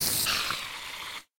Minecraft Version Minecraft Version snapshot Latest Release | Latest Snapshot snapshot / assets / minecraft / sounds / mob / spider / death.ogg Compare With Compare With Latest Release | Latest Snapshot